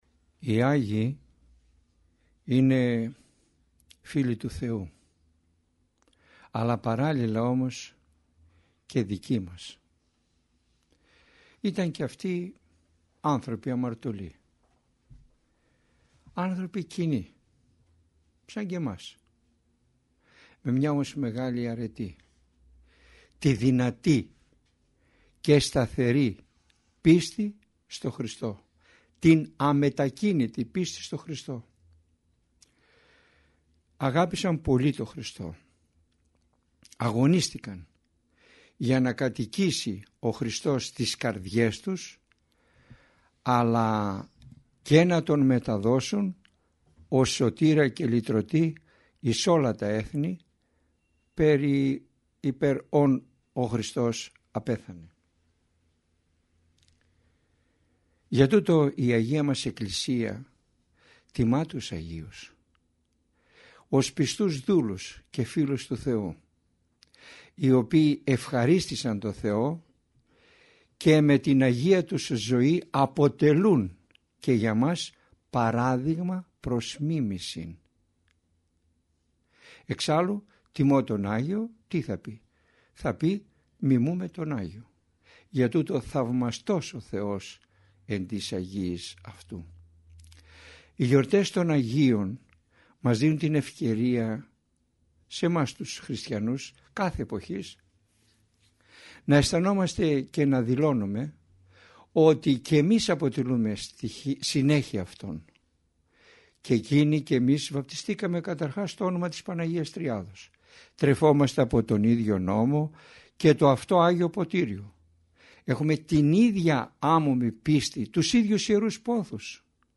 ηχογραφημένη ομιλία
Ελευθερία επιλογής Το μεγάλο πλεονέκτημα των ομιλιών Κάθε ομιλία είναι ένα ζωντανό κήρυγμα, όπου το παν εξαρτάται από τη θέληση του ακροατή˙ ο τόπος, ο χρόνος και ο τρόπος ακρόασης, το θέμα της ομιλίας εναπόκεινται στην προσωπική του επιλογή.